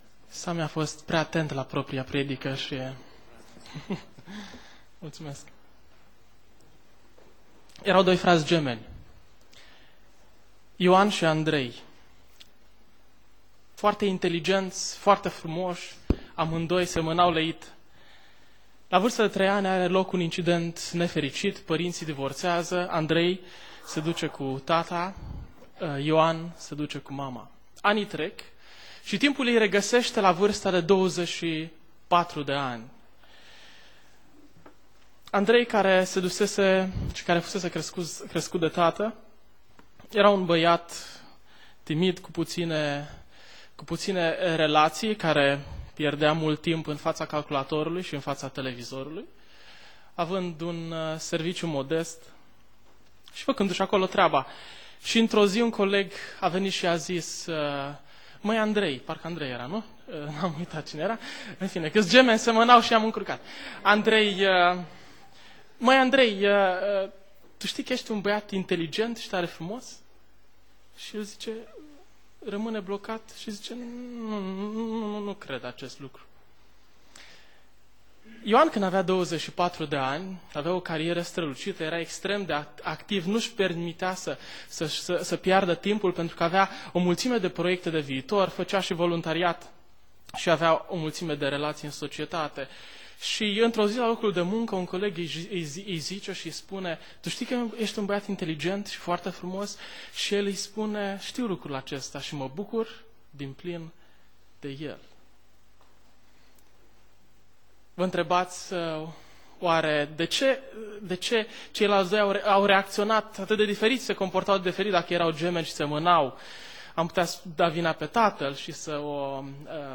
Predica 1 Samuel cap 5:1-7:17 Aplicatie